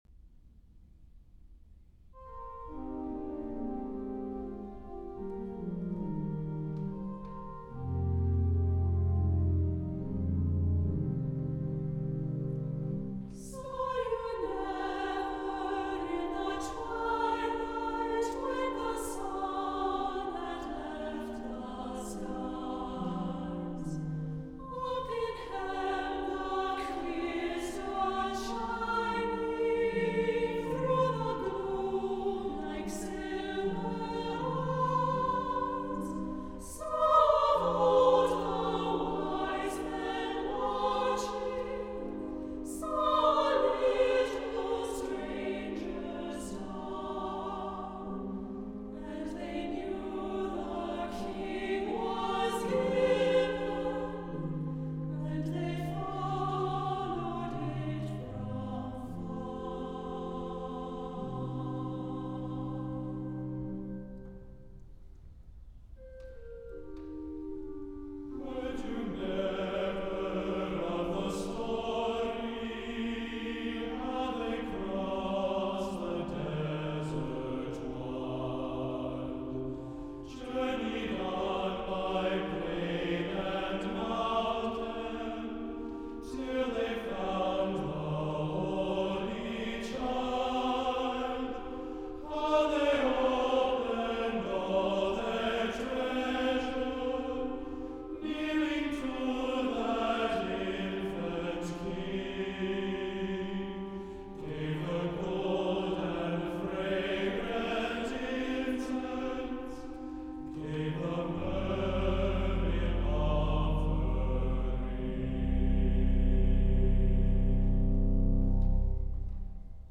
• Music Type: Choral
• Voicing: SAB
• Accompaniment: Organ
Unison men have one verse without the women singing.